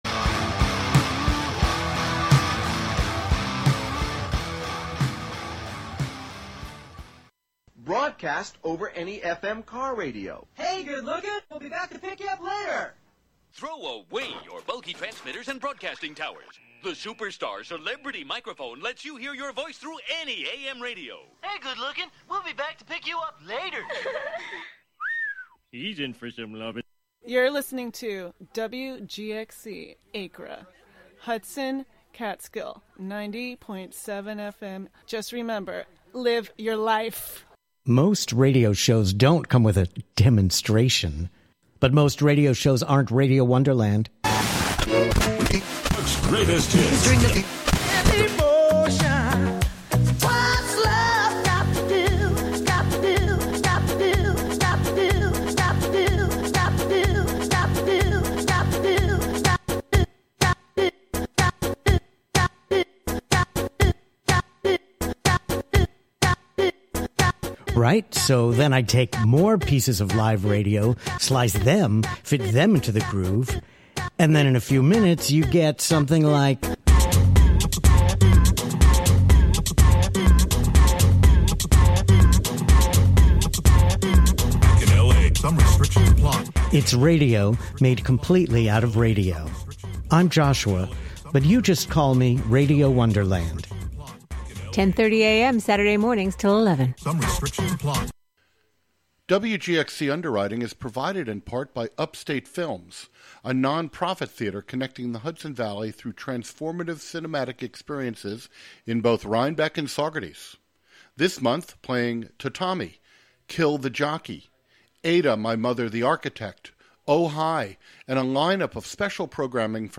Get yr weekly dose of music appreciation, wordsmithing, and community journalism filtered through the minds and voices of the Youth Clubhouses of Columbia-Greene, broadcasting live out of the Catskill Clubhouse on Fridays at 6 p.m. and rebroadcast Sundays at 7 a.m.